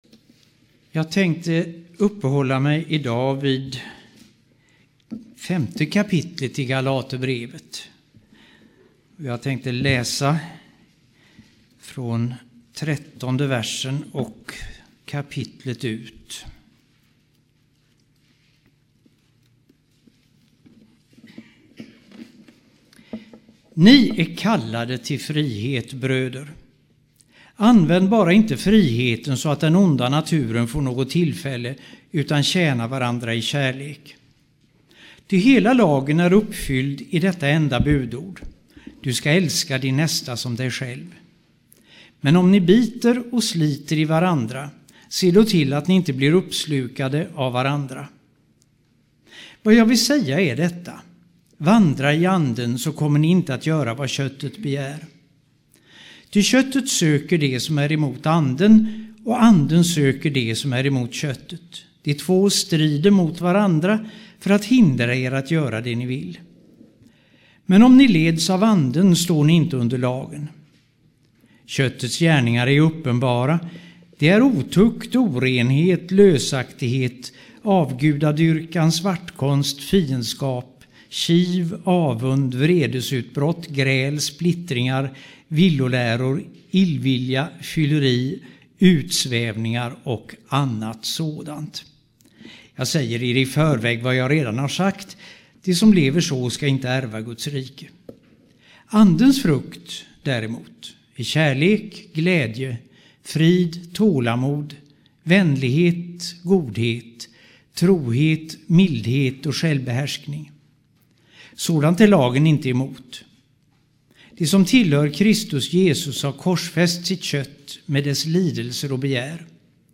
2007-04-21 Bibelstudium av